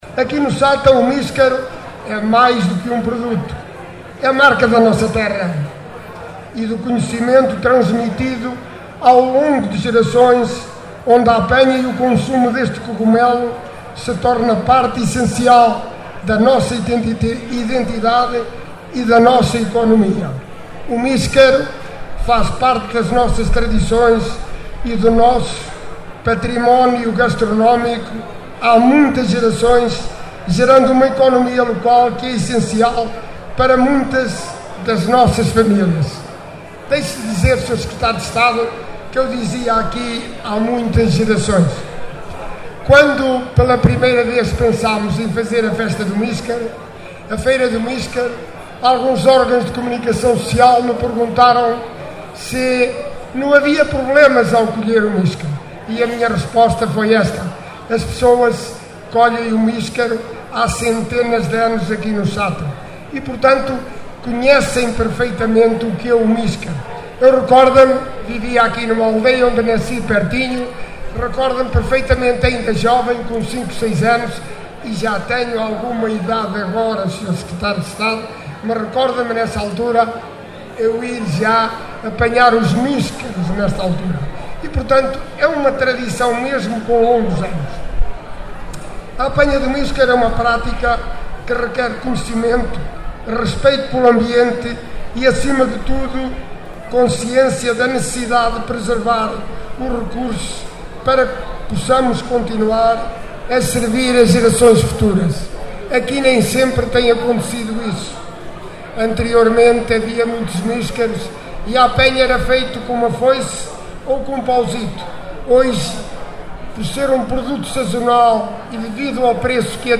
Alexandre Vaz, Presidente do Município de Sátão, no seu discurso afirmou, “o míscaro é mais que um produto, é marca da nossa terra…”, “faz parte das nossas tradições e do nosso património gastronómico há muitas gerações…”.